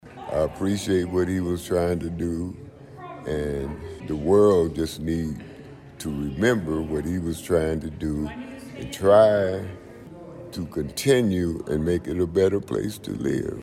Special evening service honors the teachings, memory and legacy of Dr. Martin Luther King Jr Monday